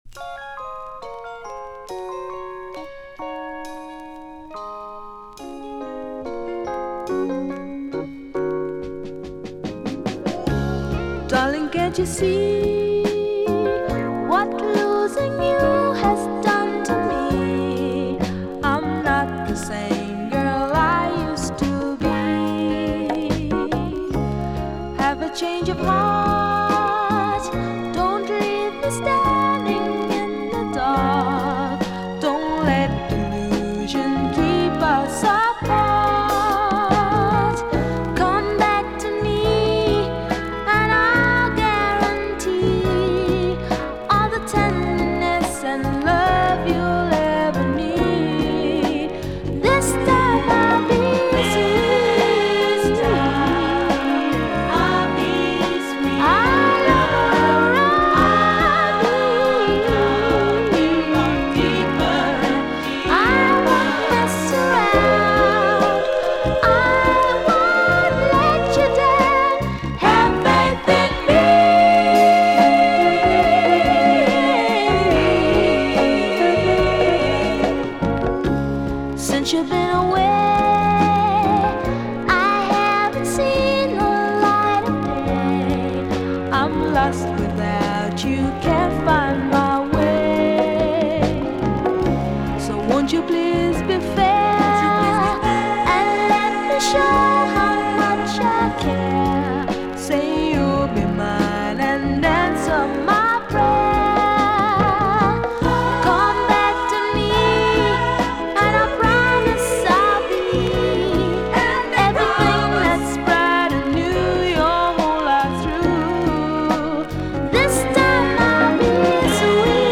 It’s like a touch over 70